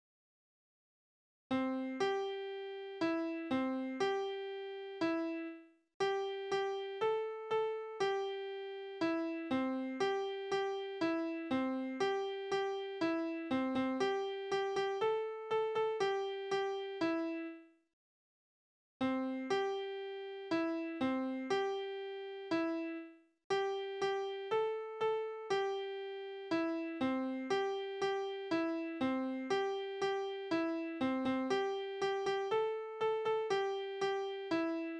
Kindertänze: Die Laterne
Tonart: C-Dur
Taktart: 4/4
Tonumfang: große Sexte